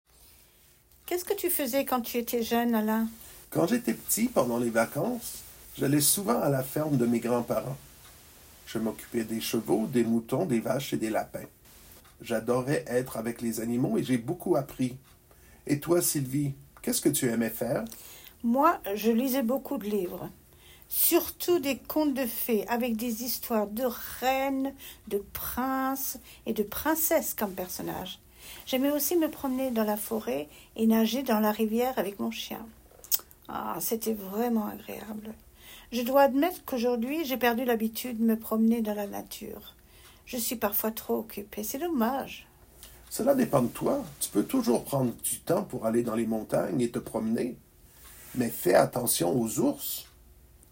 Dialogue – Un 10 | FrenchGrammarStudio